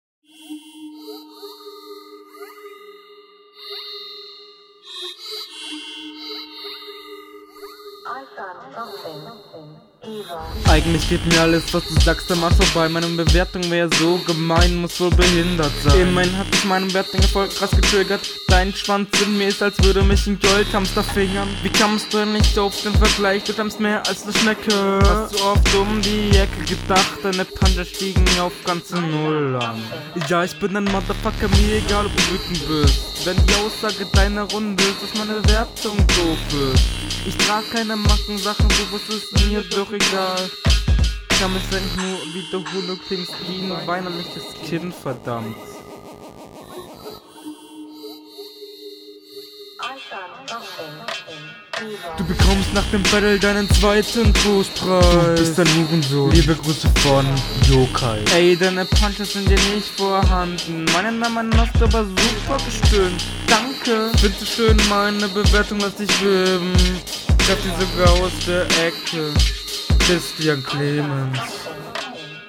Du bist leider nicht ganz im takt, ganz Flowlich mit deinem Gegner nicht mithalten, Textlich …
Was mir direkt auffällt ist dein asynchroner Flow, der öfters mal am Beat vorbeigeht.